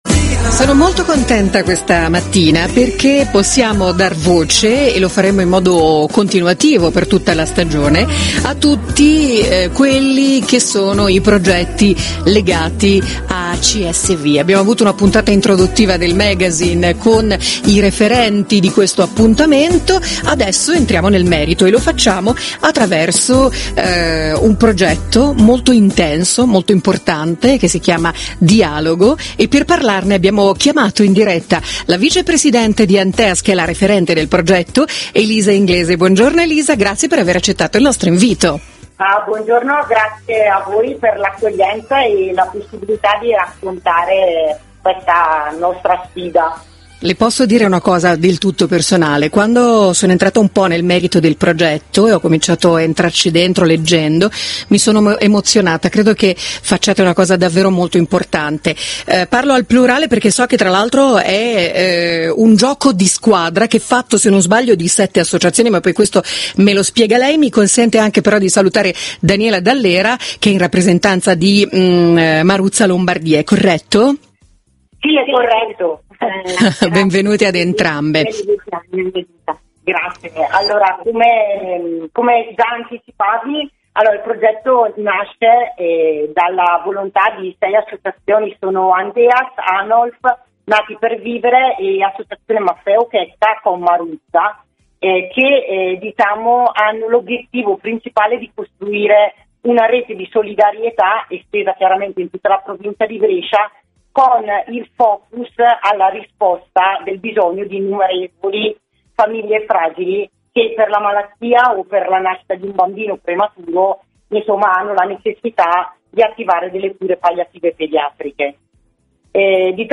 Radio Bresciasette intervista ANTEAS - Cisl Brescia
Radio-Bresciasette-Intervista-ad-ANTEAS-Brescia.mp3